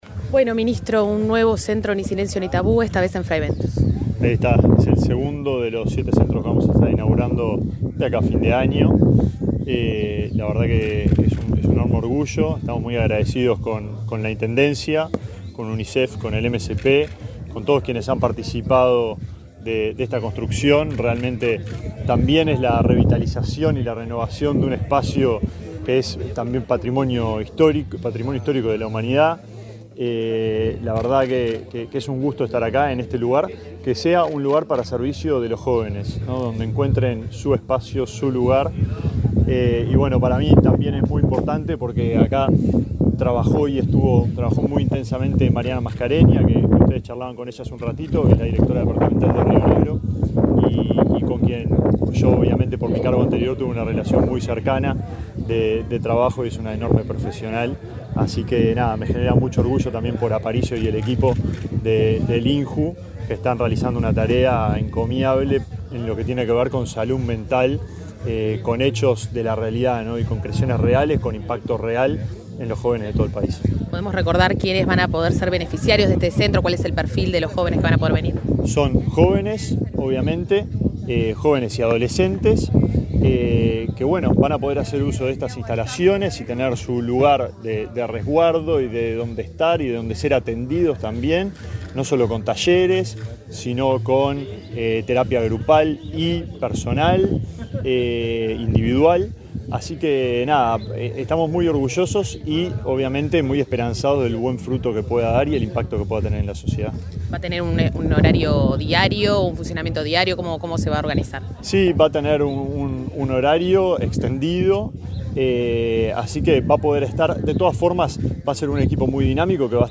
Entrevista al ministro de Desarrollo Social, Alejandro Sciarra
Entrevista al ministro de Desarrollo Social, Alejandro Sciarra 22/10/2024 Compartir Facebook X Copiar enlace WhatsApp LinkedIn El ministro de Desarrollo Social, Alejandro Sciarra, dialogó con Comunicación Presidencial, antes de participar en la presentación de las instalaciones del centro Ni Silencio Ni Tabú, para la atención de la salud mental de adolescentes y jóvenes.